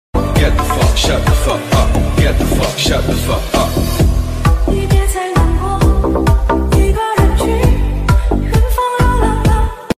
me me me meowwww neoww sound effects free download